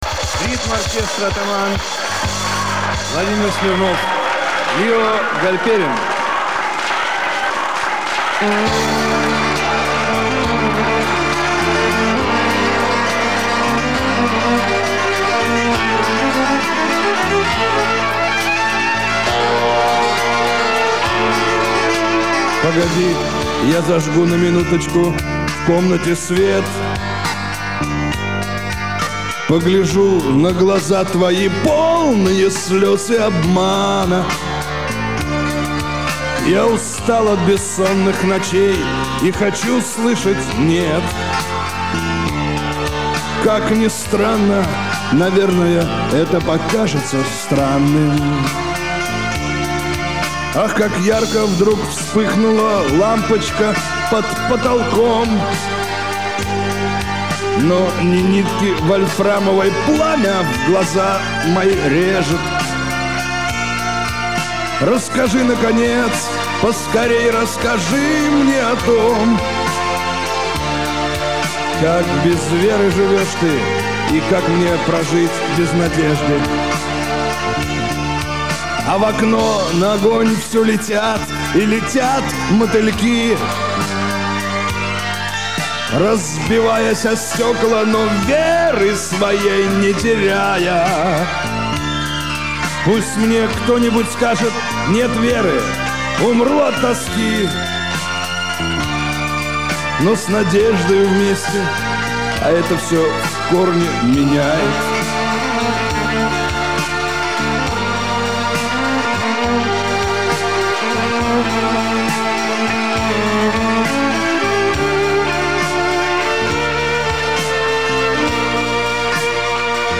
Она была выпущена на студийной кассете.